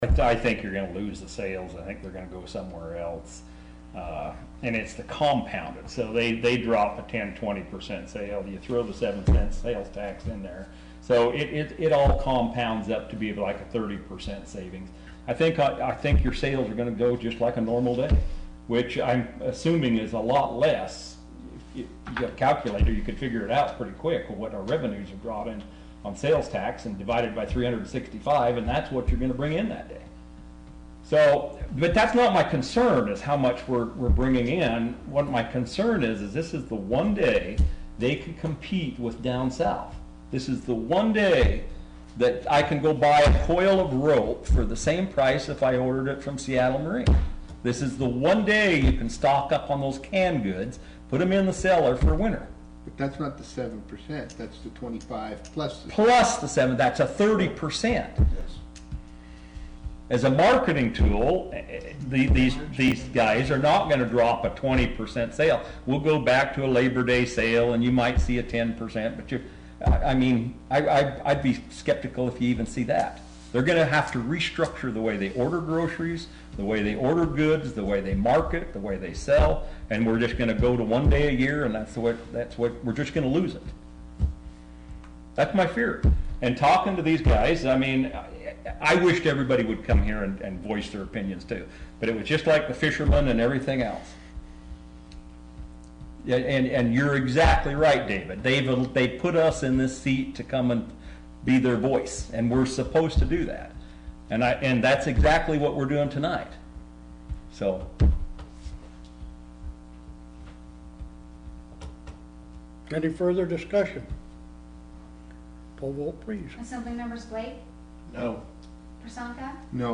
Wrangell Borough Assembly Meeting 8-23-16